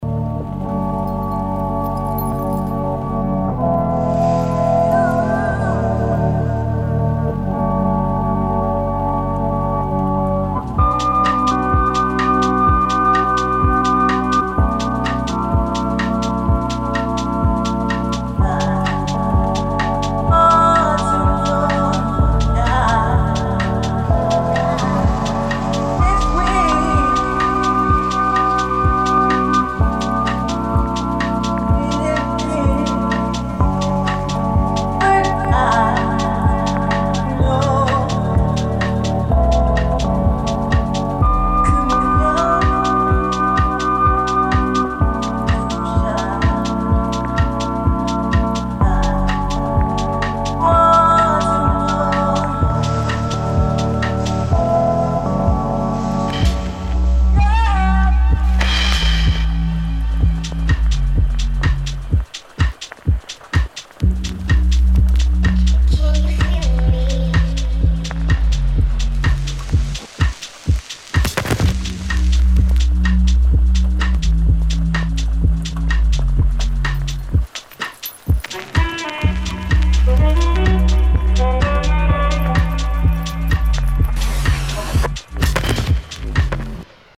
DUBSTEP | TECHNO